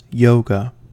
yoga-1-us.mp3